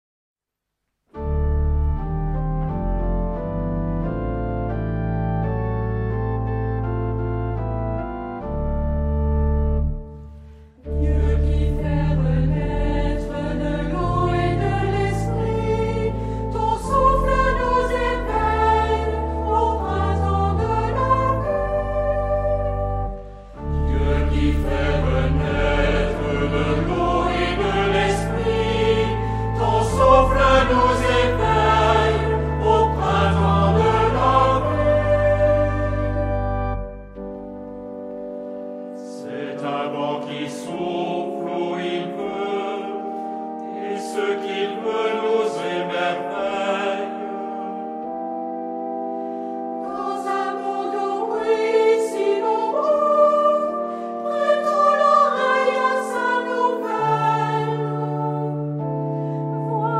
Genre-Style-Form: Hymn (sacred) ; Sacred
Mood of the piece: lively ; supple
Type of Choir: unisson  (1 voices )
Instruments: Organ (1)
Tonality: D minor